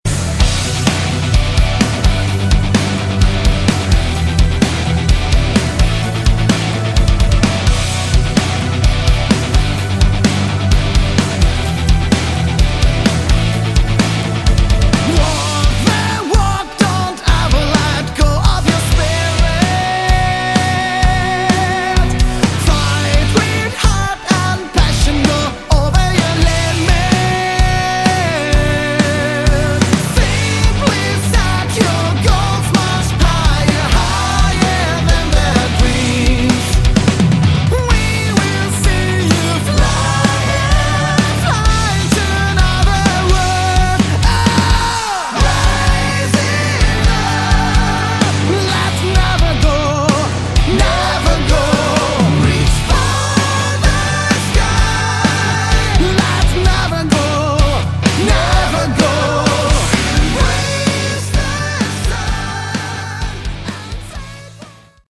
Category: Melodic Rock
vocals
guitar
bass
keyboard
drums